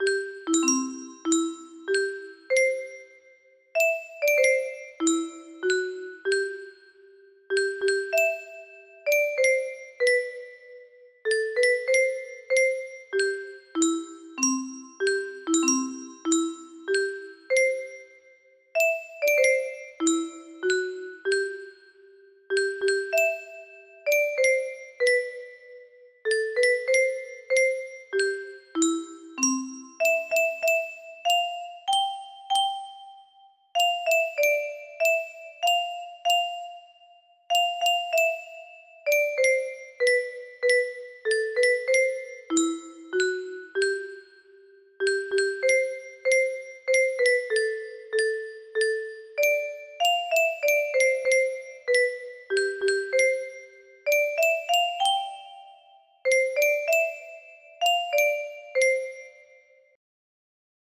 The Star Spangled Banner- Francis Scott Key music box melody